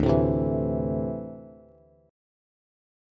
D7sus2 chord